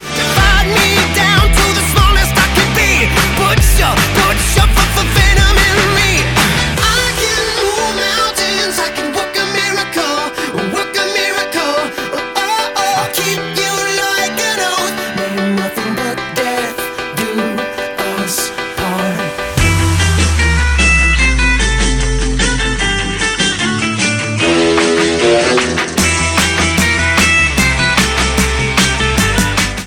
• Pop Rock